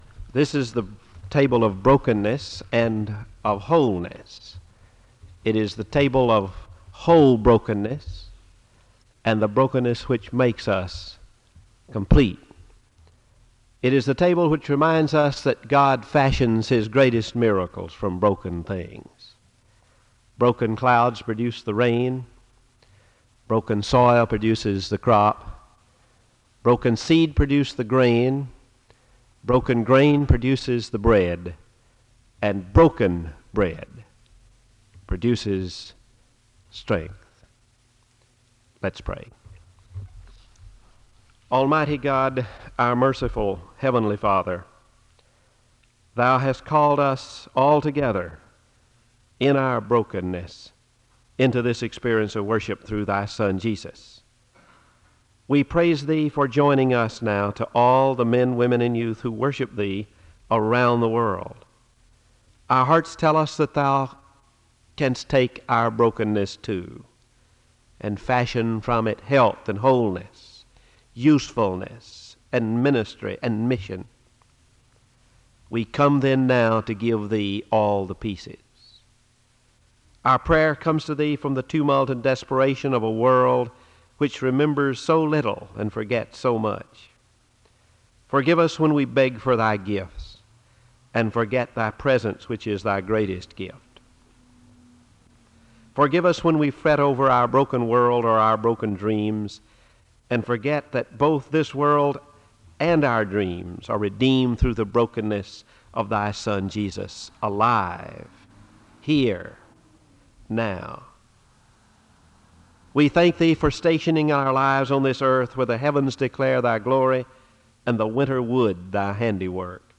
Thanksgiving Day sermons
In Collection: SEBTS Chapel and Special Event Recordings SEBTS Chapel and Special Event Recordings